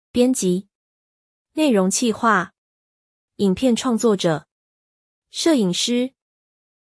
編集者 編輯 biān jí 出版社やメディア業界でよく使う。